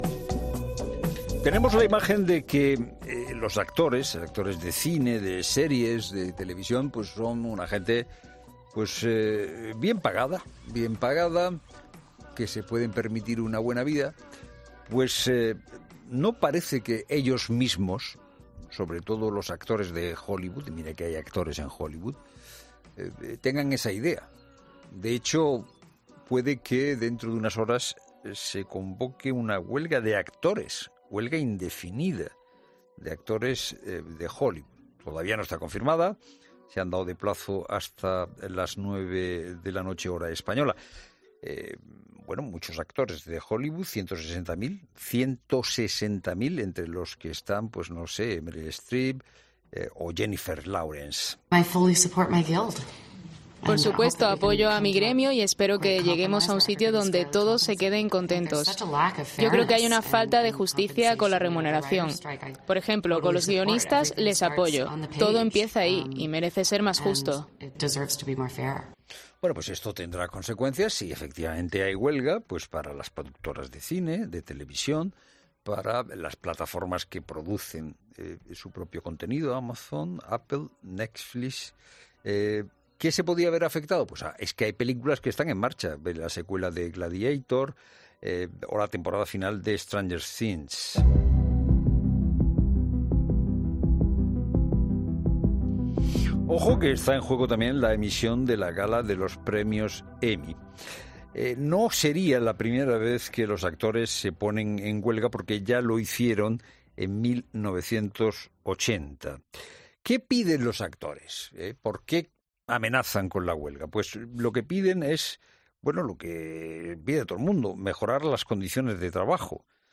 En 'La Tarde', dos personas nos cuentan de primera mano cómo es la realidad de la situación y las demandas del sector.